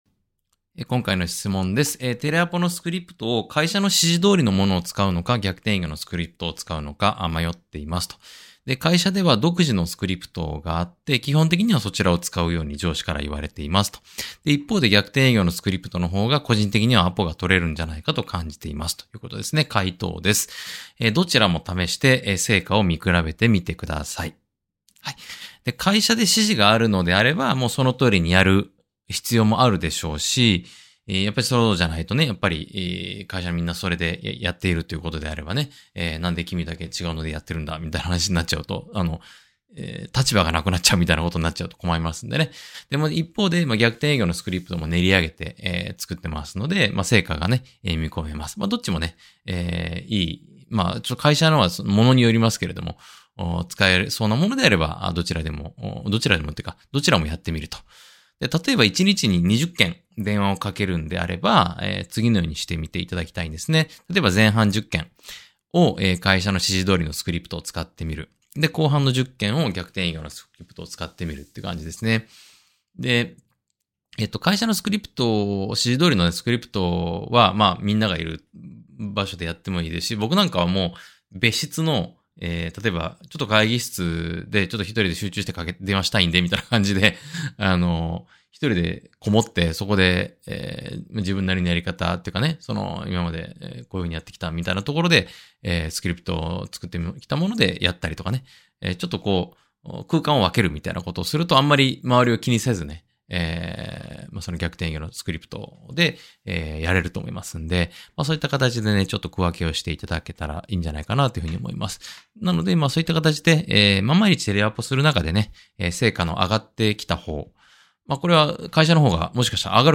音声回答（︙をクリック→ダウンロード）